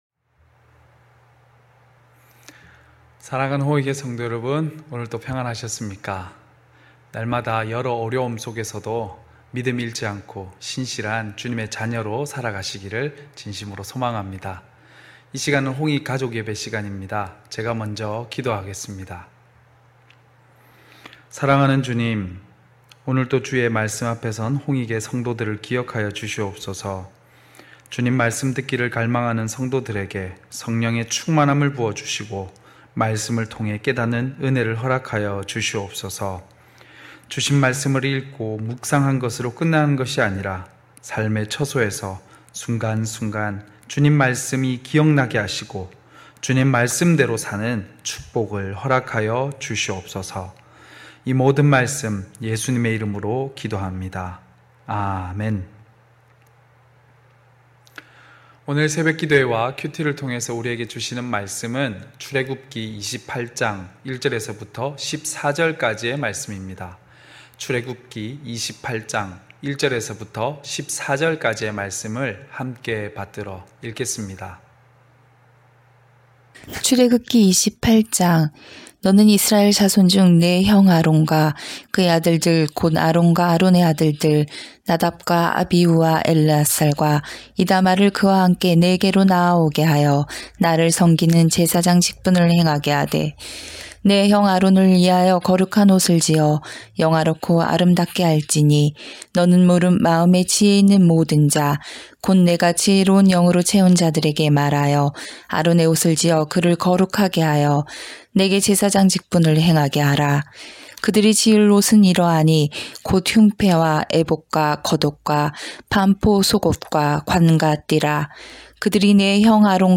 9시홍익가족예배(10월7일).mp3